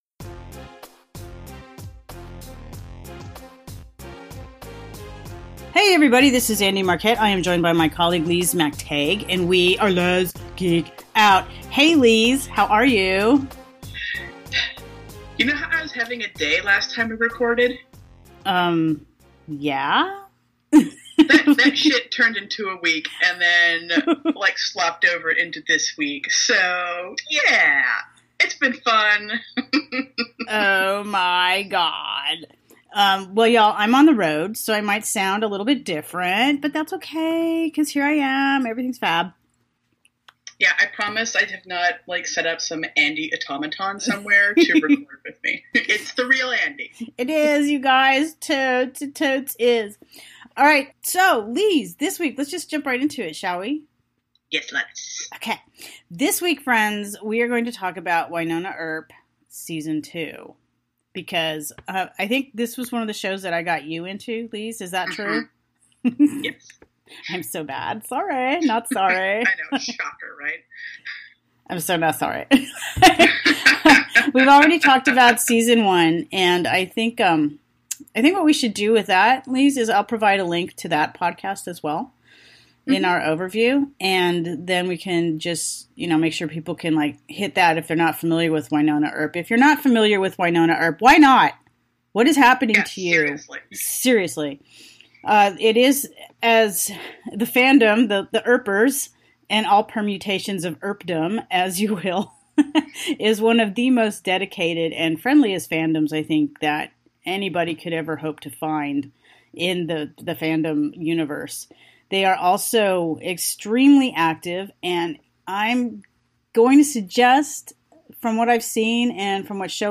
(Note: Because of the way this episode was recorded, the audio isn’t as clean as usual. Everything is audible, but some of the hiccups that get edited out couldn’t be this time.)